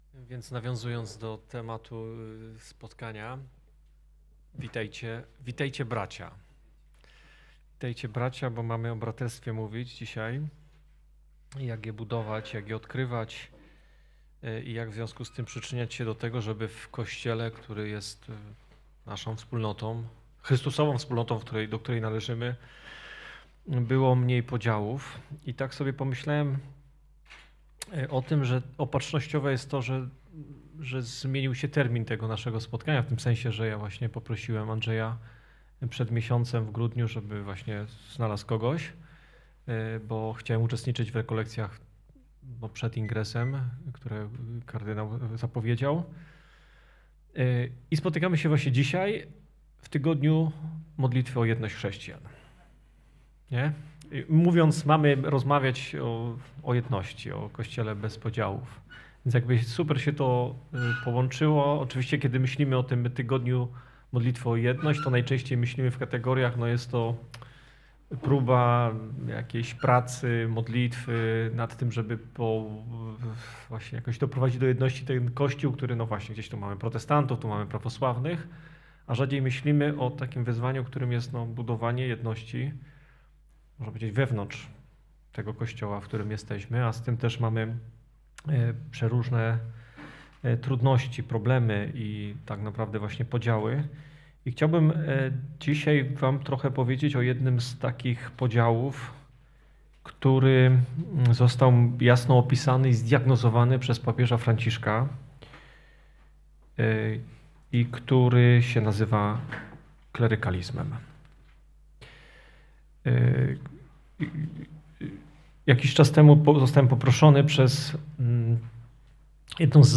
Braterstwo chrzcielne – spotkanie o budowaniu Kościoła bez podziałów, w duchu nauczania papieża Franciszka, współodpowiedzialności i chrześcijańskiej służby.